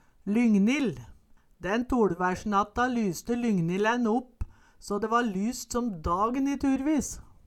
Høyr på uttala Ordklasse: Substantiv hankjønn Attende til søk